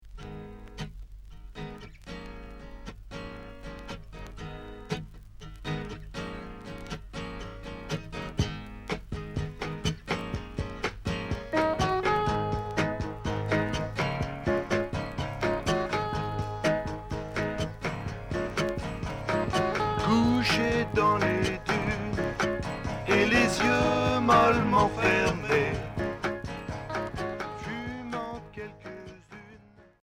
instrumental
Pop Unique 45t retour à l'accueil